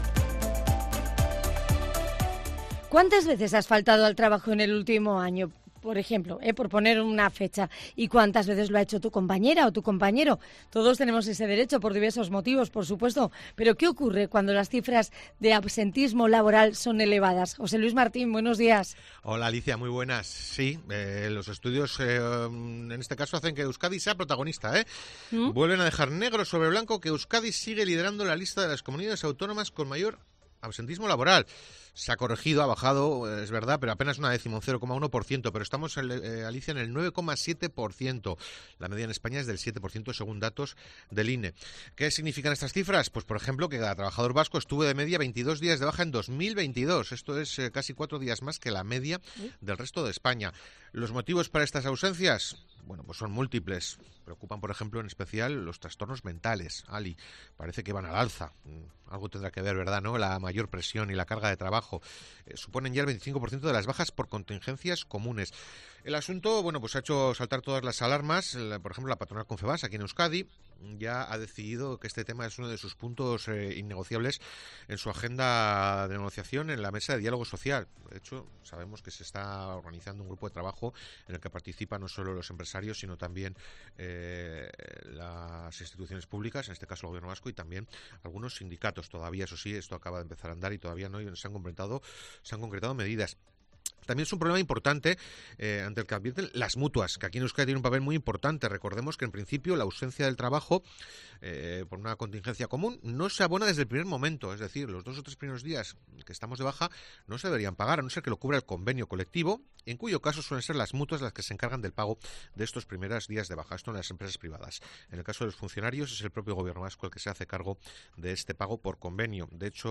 COPE Euskadi pregunta a los expertos por los motivos y consecuencias del alto nivel de absentismo laboral en la comunidad del País Vasco